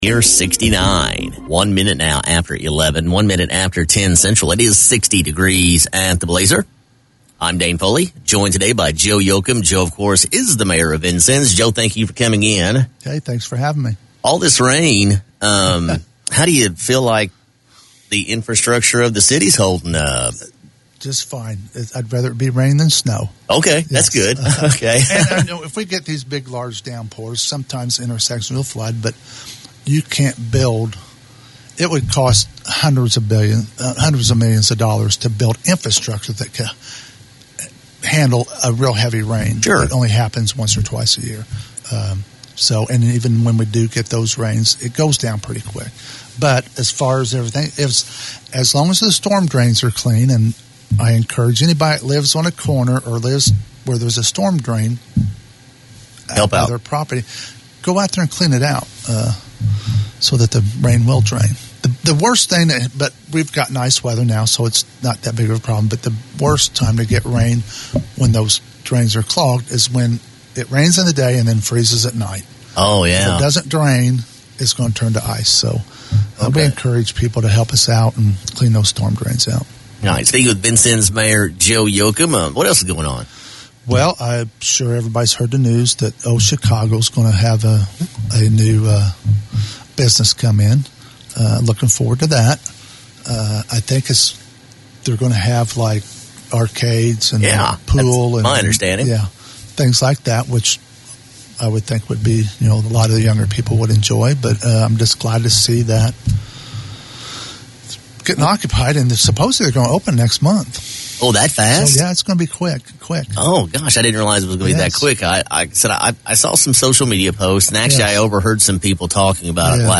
Vincennes Mayor Joe Yochum appeared on WVUB’s Community Connection today and confirmed that a new business will move into the Old Chicago’s Pizza building at Main and 6th Street.  Listen to the full interview below.